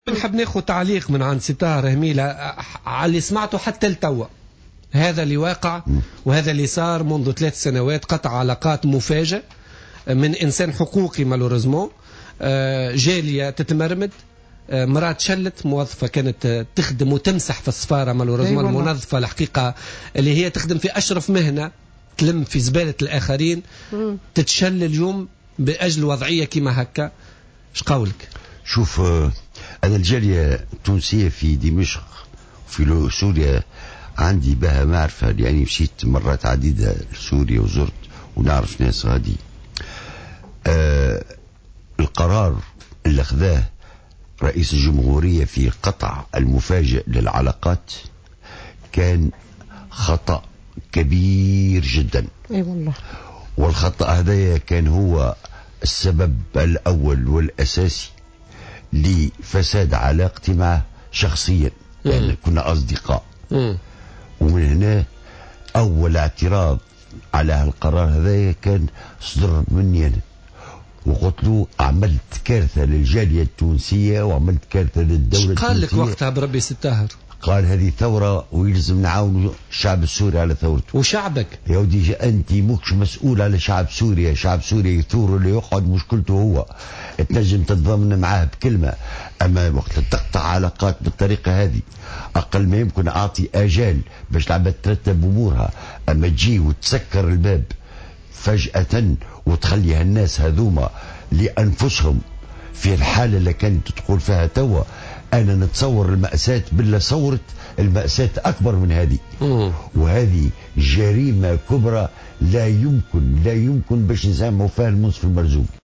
قال النائب السابق في المجلس الوطني التأسيسي الطاهر هميلة في برنامح "بوليتيكا" على "جوهرة أف أم" اليوم الاثنين 25 ماي 2015 إنه لا يمكن "مسامحة" الرئيس السابق منصف المرزوقي بسبب قراره غلق السفارة التونسية في سوريا.